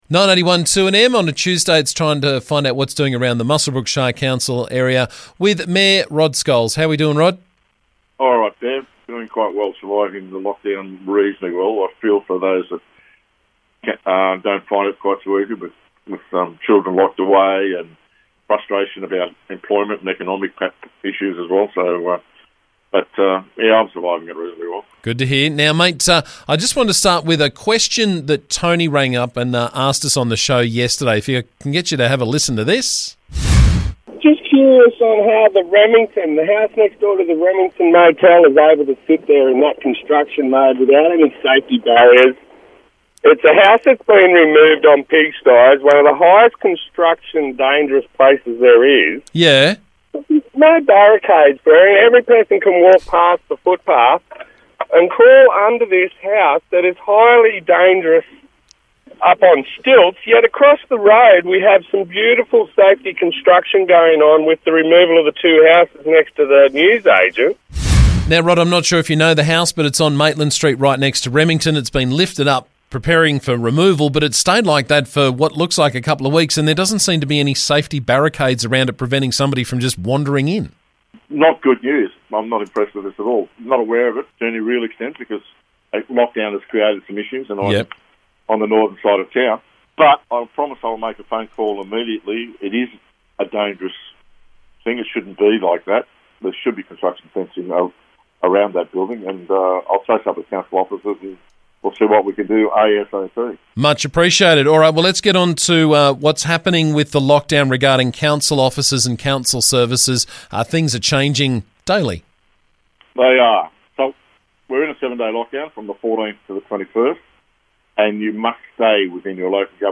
Muswellbrook Shire Council Mayor Rod Scholes joined me to talk about the latest from around the district.